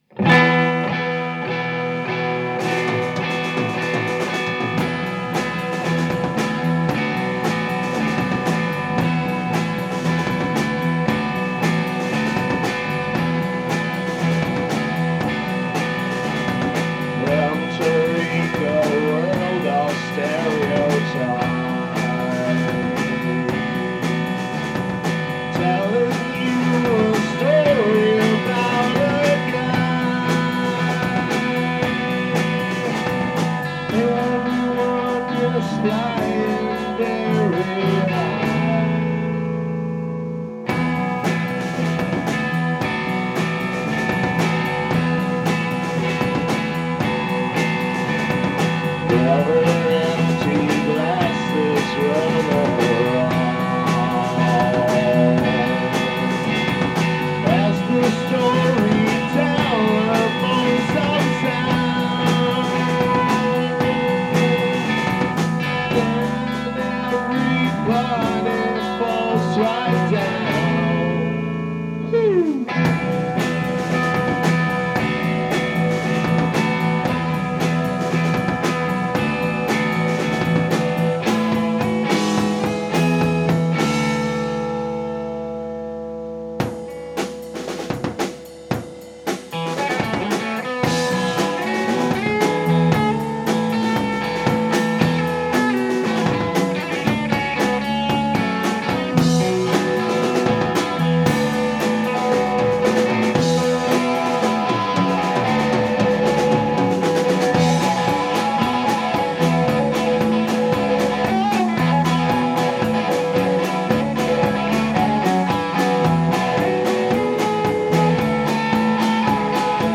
Un suono ruvido e verace, carico di flanella e sudore